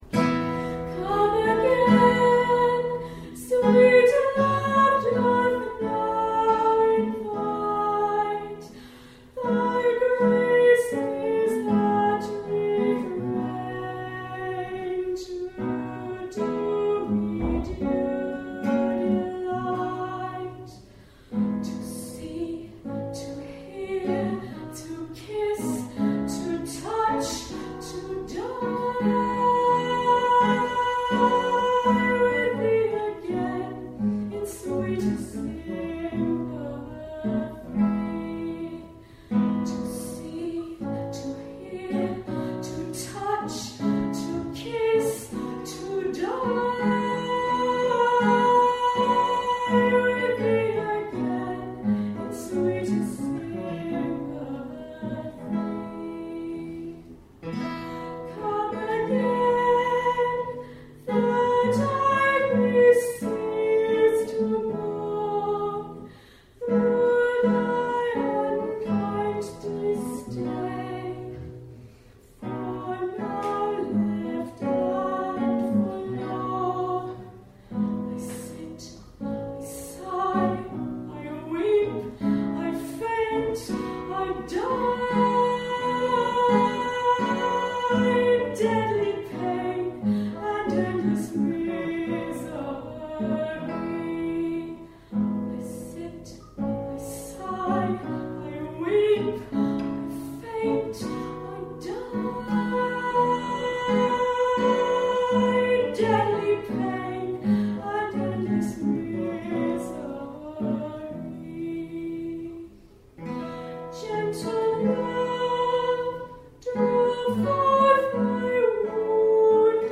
during a lunchtime concert on 4 May at the Elgar School of Music in Worcester